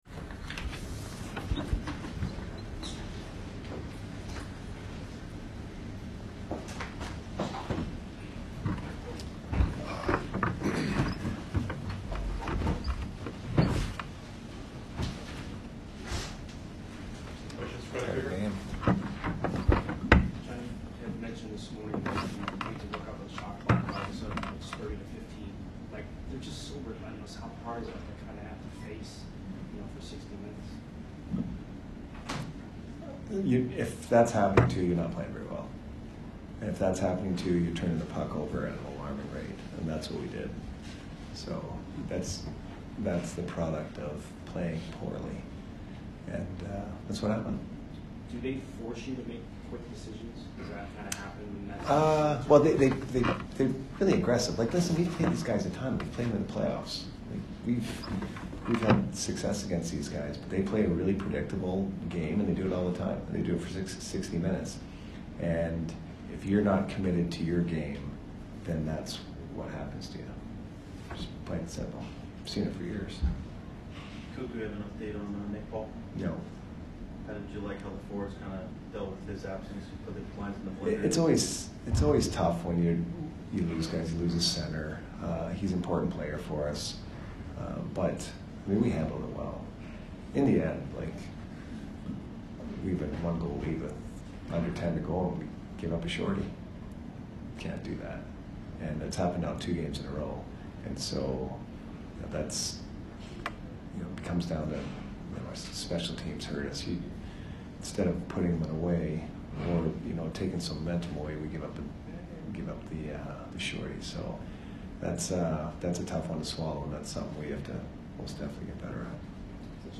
Head Coach Jon Cooper Post Game 11/3/22 vs CAR